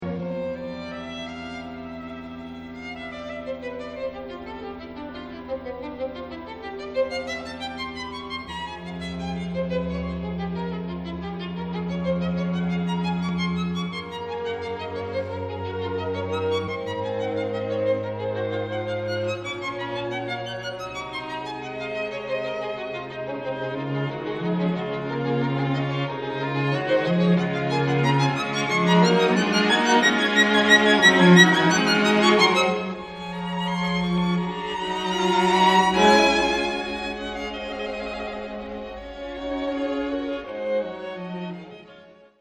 muziektheorieanalyse klassieke stukken  > Beethoven: strijkkwartet in F gr.t.  op. 59 nr.1